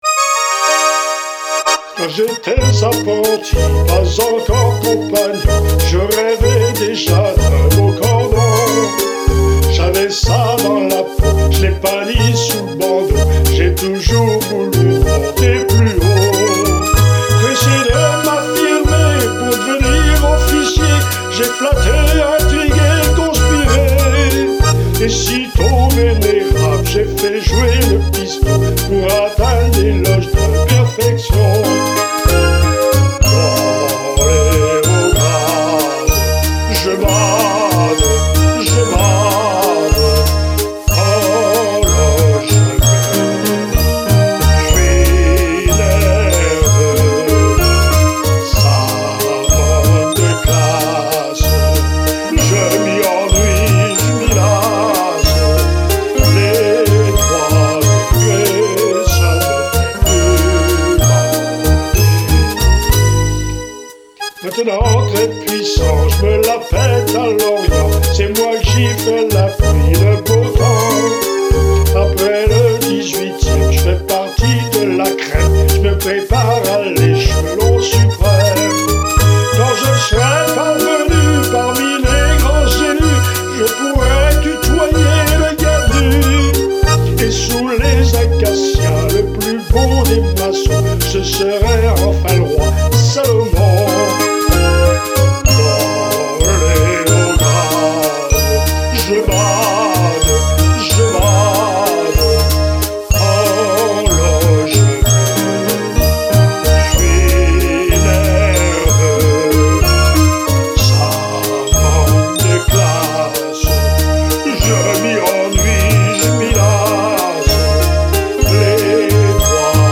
Chant solo